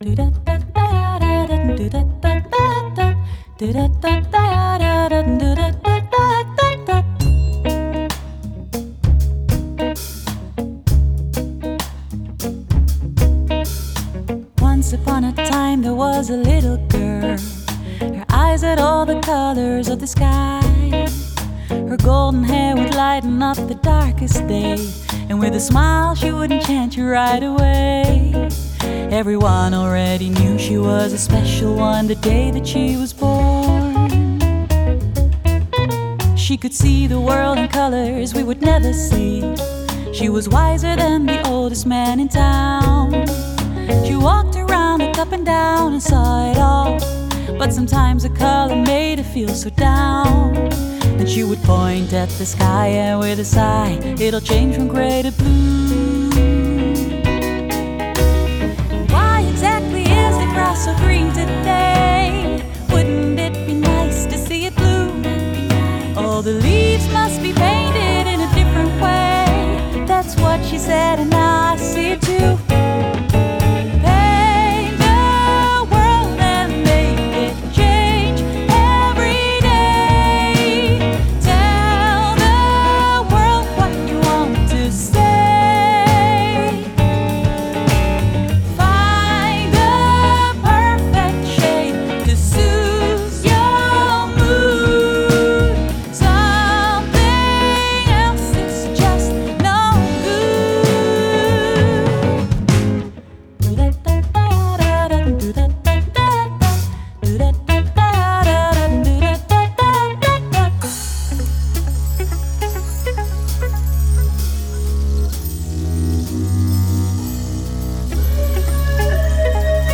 Genre: Jazz/Soul/Pop Vocals